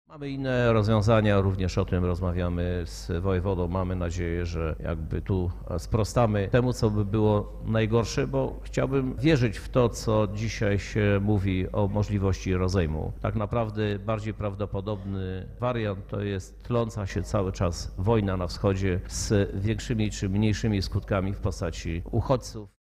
• mówi Krzysztof Żuk, prezydent Lublina.